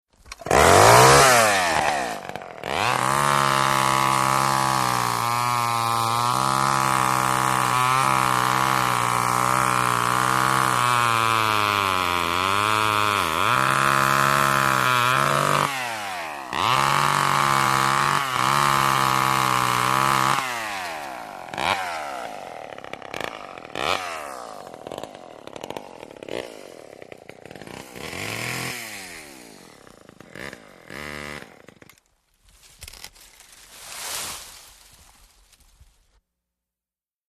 ChainsawStartCutt PE699001
MACHINES - CONSTRUCTION & FACTORY CHAINSAW: EXT: Starting and cutting small tree for 19 seconds, revs at end, pull away from mic, off, tree fall.